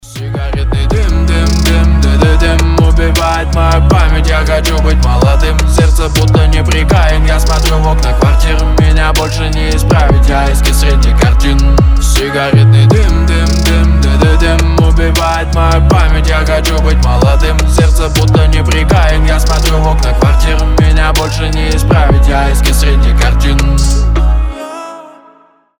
мужской голос
лирика
Хип-хоп